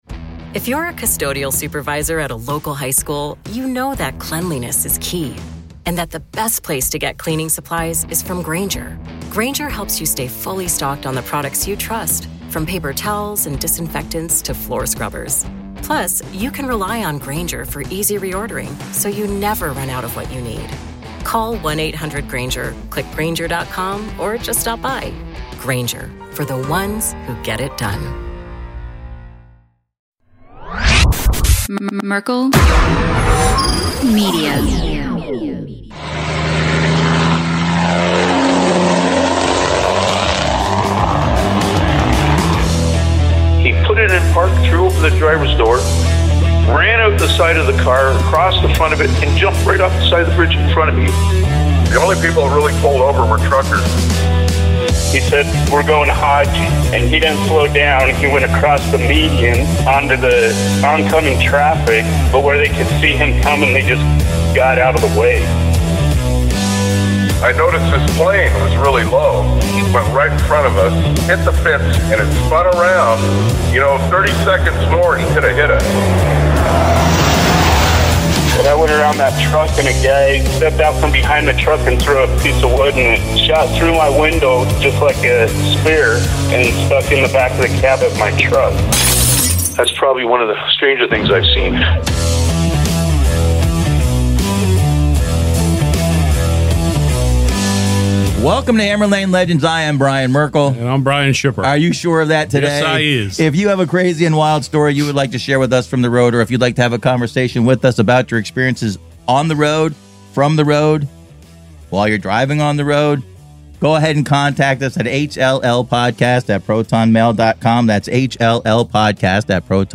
There was a lot of great conversation and laughs throughout the episode.